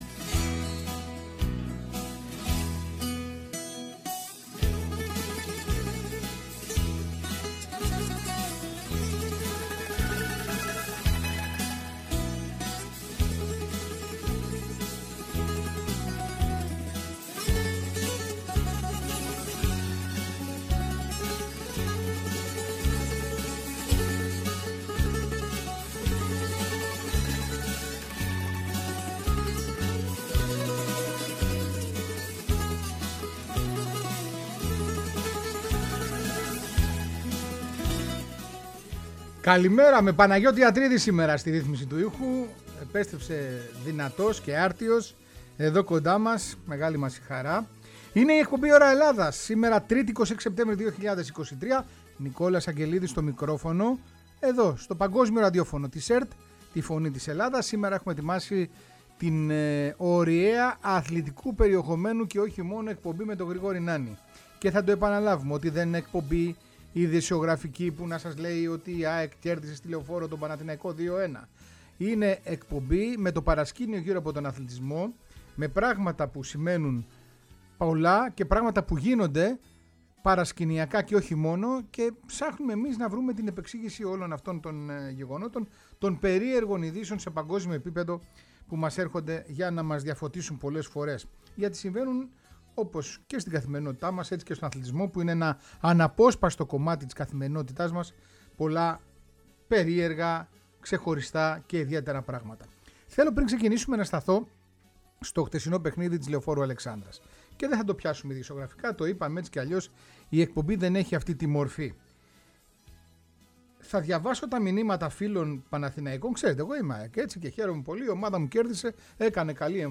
Η εκπομπή ΩΡΑ ΕΛΛΑΔΑΣ ταξιδεύει με ξεχωριστές νότες σε κάθε γωνιά του πλανήτη αναζητώντας τις ειδήσεις εκείνες, αθλητικές και μη, που δεν ακούσατε ή προσέξατε. Στο στούντιο